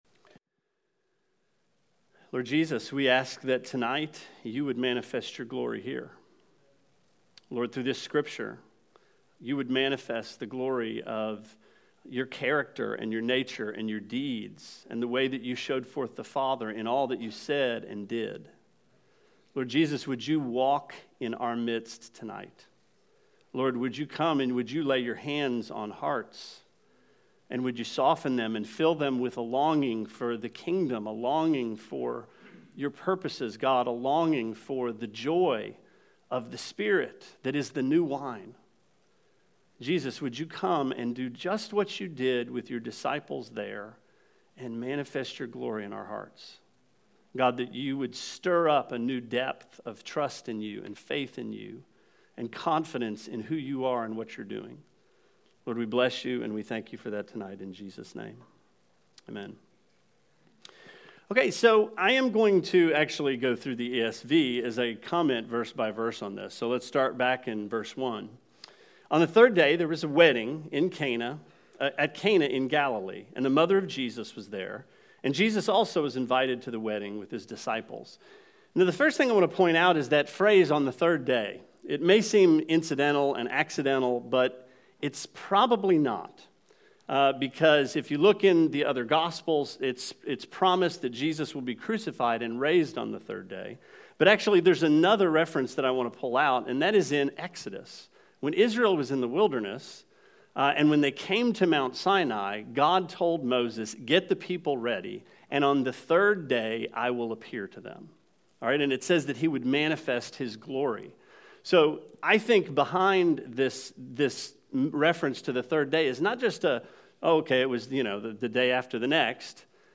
Sermon 09/14: Wedding at Cana